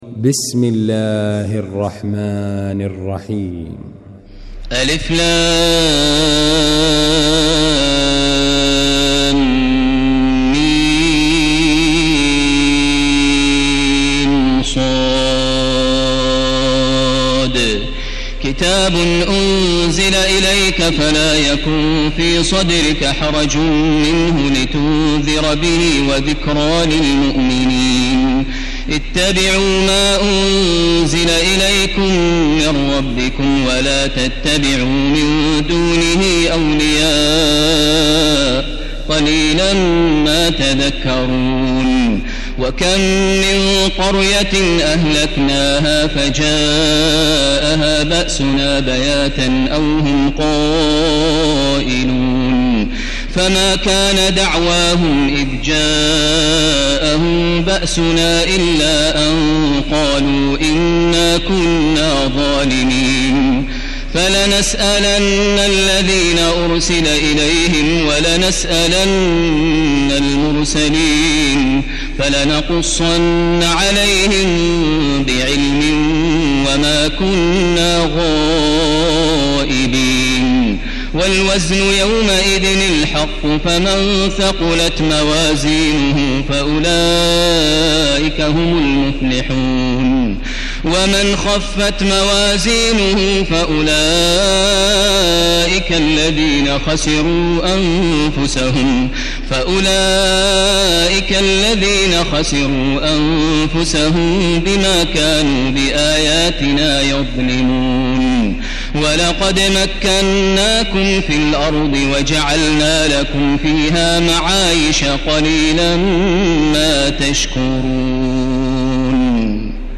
المكان: المسجد الحرام الشيخ: معالي الشيخ أ.د. بندر بليلة معالي الشيخ أ.د. بندر بليلة خالد الغامدي فضيلة الشيخ ماهر المعيقلي الأعراف